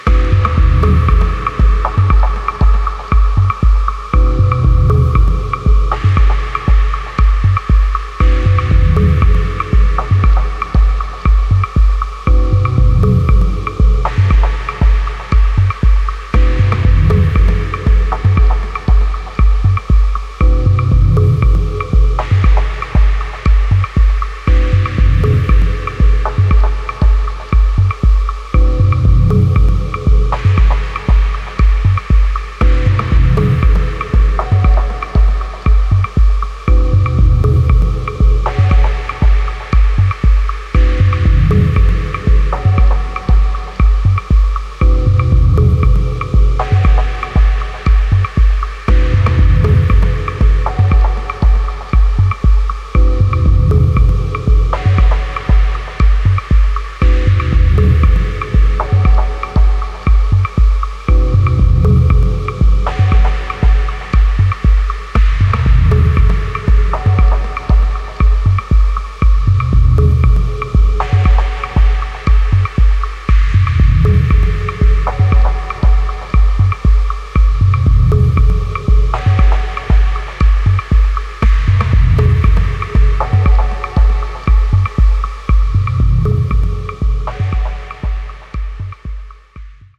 豊潤なテクスチャーで覆い尽くすディープ・ダブ・テック